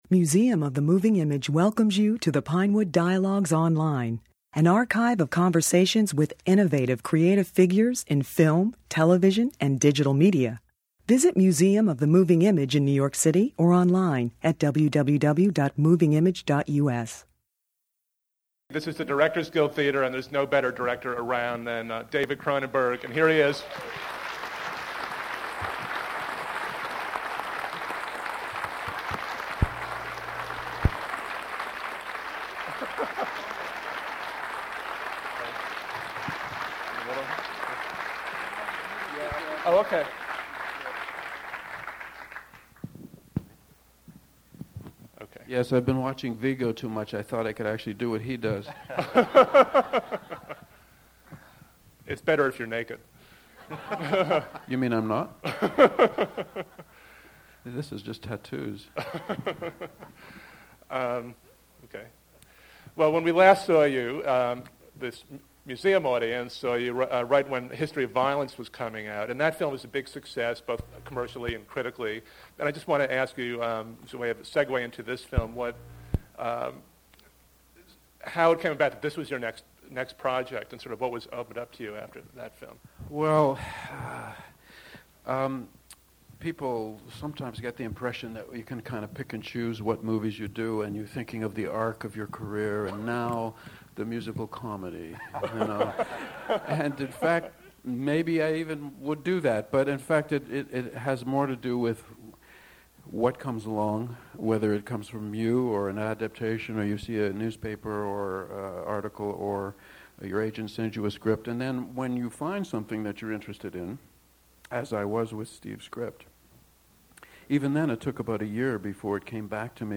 Cronenberg and screenwriter Steve Knight (Dirty Pretty Things) discussed the movie at a Museum screening prior to its successful U.S. release.